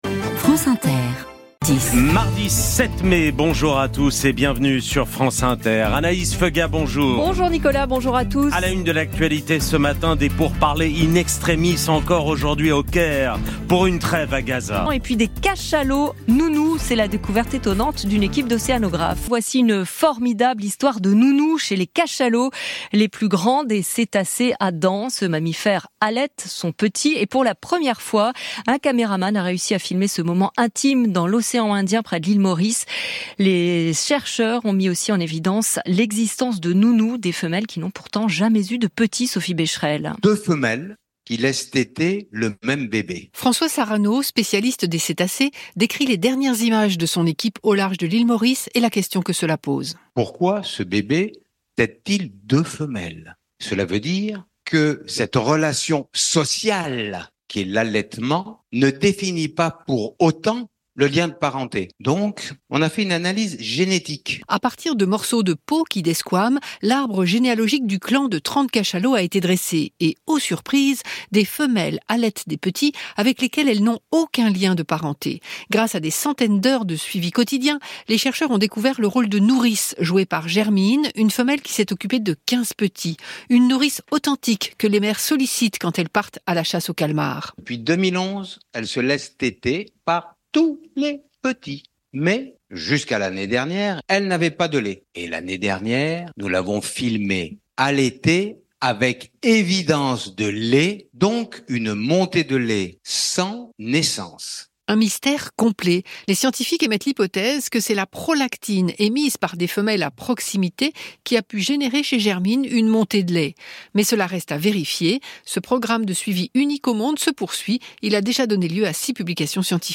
Extrait du journal de 7h du 7 mai, avec François Sarano sur l’allaitement des cachalots